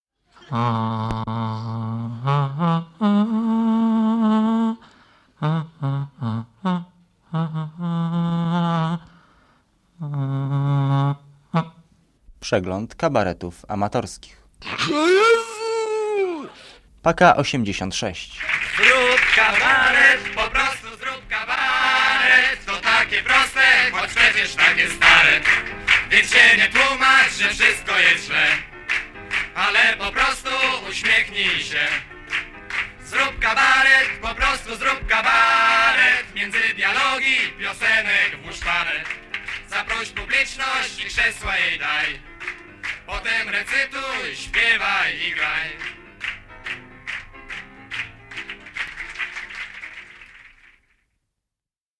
PAKA '86 - Przegląd Kabaretów Amatorskich